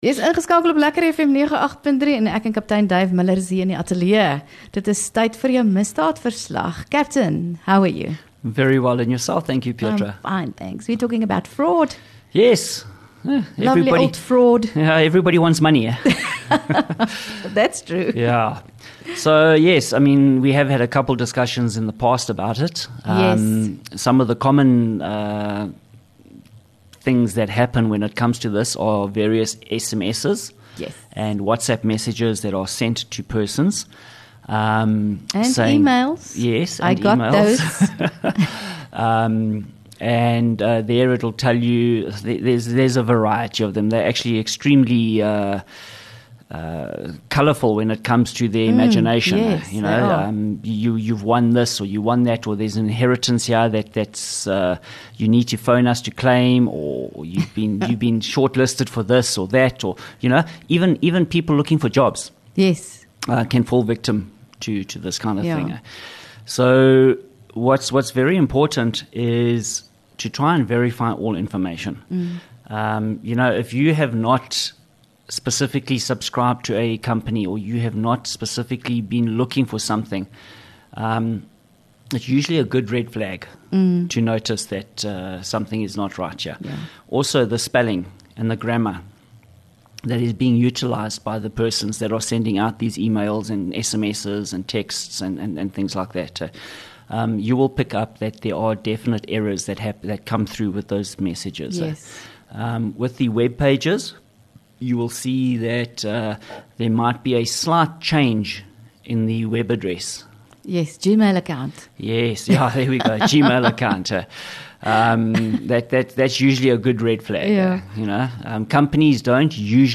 LEKKER FM | Onderhoude 16 Jul Misdaadverslag